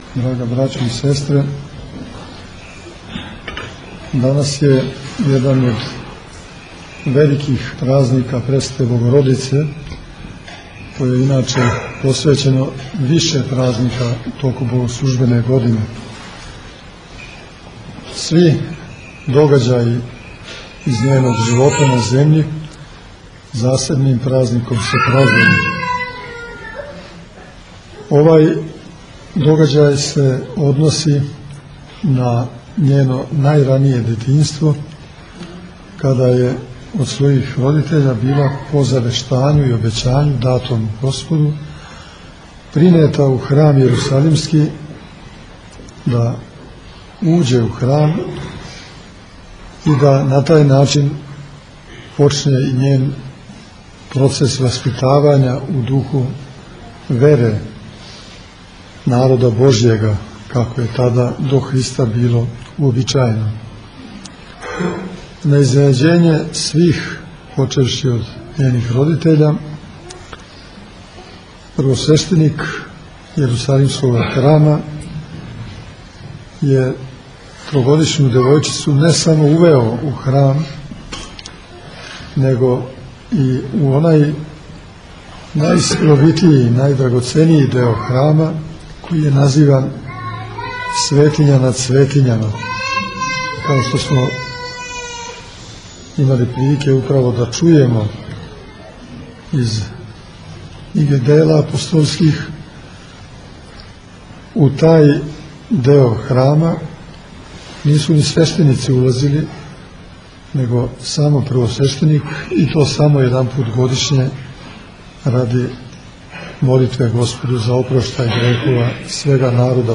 На празник Ваведења Пресвете Богородице, 4. децембра 2010. године, братство манастира Бођани прославило је своју славу.
Светом архијерејском Литургијом началствовао је Његово Преосвештенство Епископ новосадски и бачки Господин др Иринеј, уз саслужење Његовог Преосвештенства Епископа јегарског Господина др Порфирија.